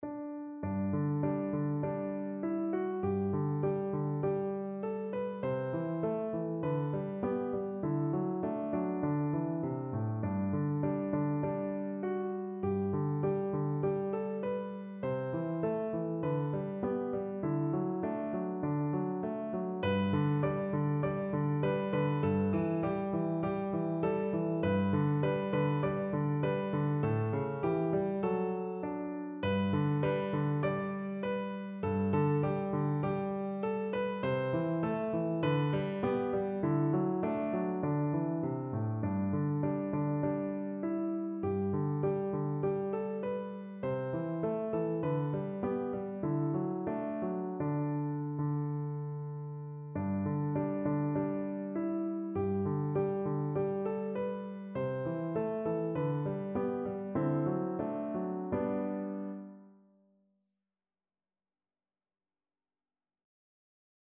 Traditional Trad. Dublin City (Spanish Lady) (Easy) Piano version
G major (Sounding Pitch) (View more G major Music for Piano )
4/4 (View more 4/4 Music)
Piano  (View more Easy Piano Music)
Traditional (View more Traditional Piano Music)